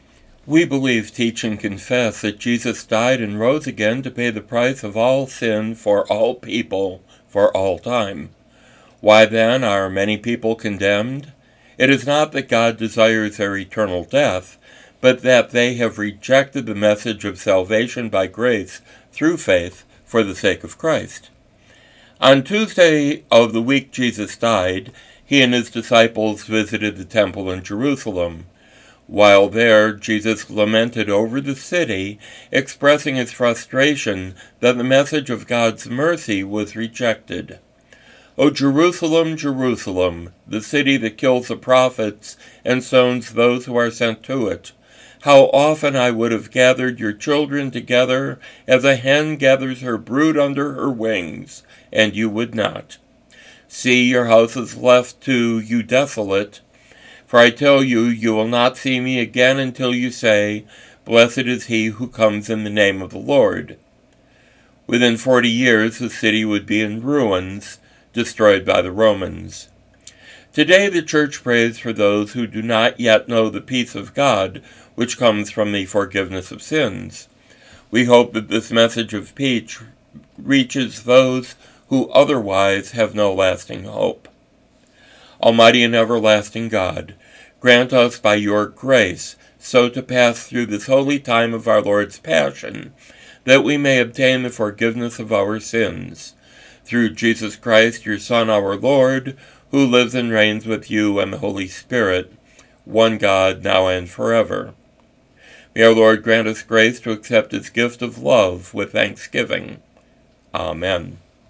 Broadcast